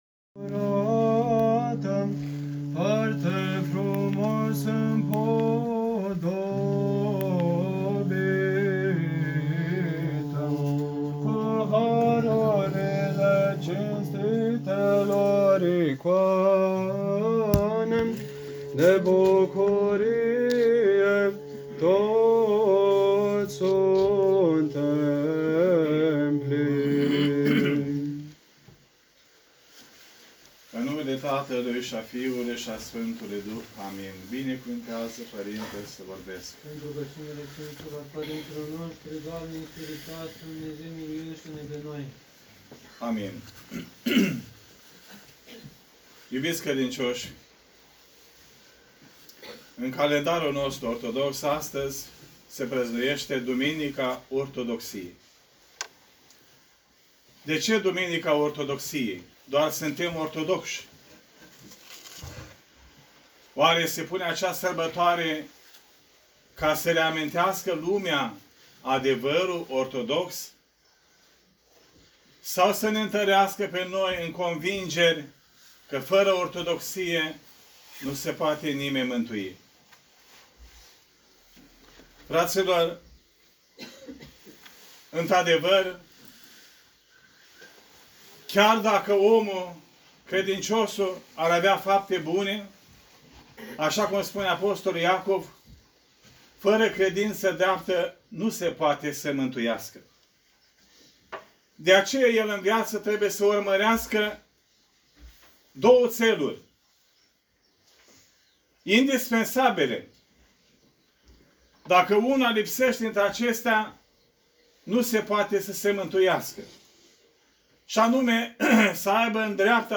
Predici